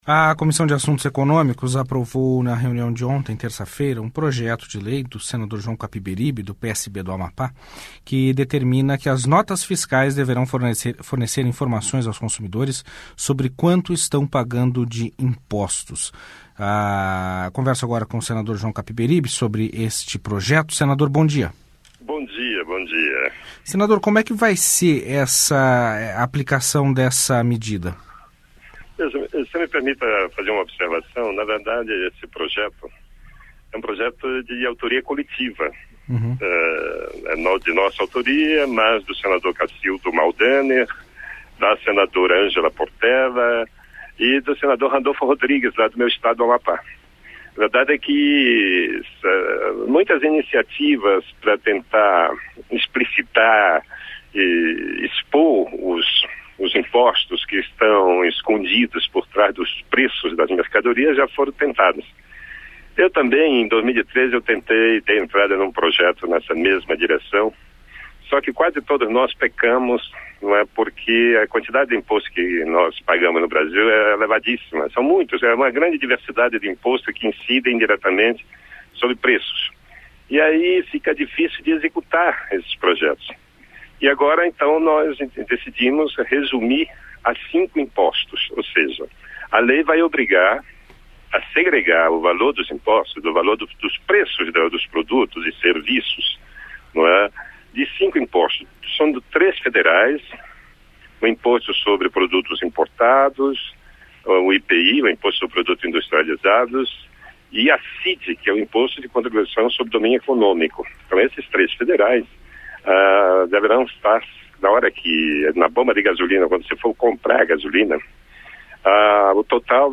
Entrevista com o senador João Capiberibe.